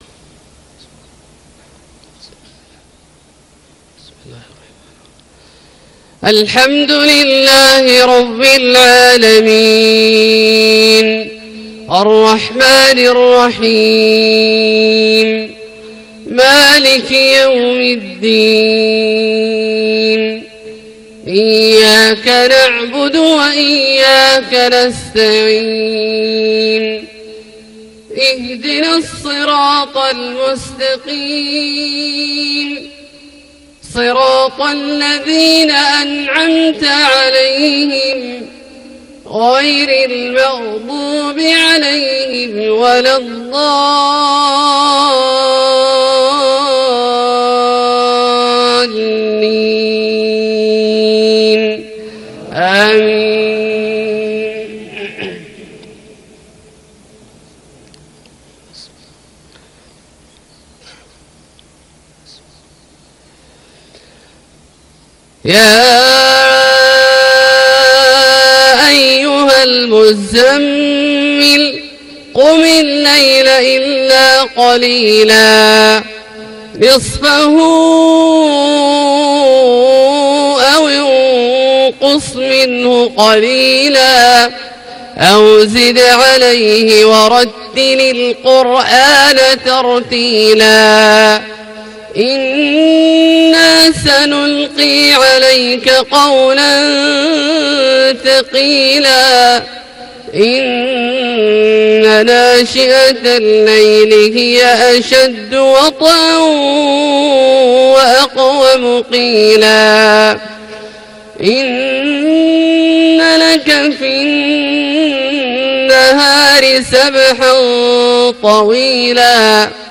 صلاة الفجر ٣ رمضان ١٤٣٨ سورة المزمل > ١٤٣٨ هـ > الفروض - تلاوات عبدالله الجهني